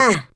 20 RSS-VOX.wav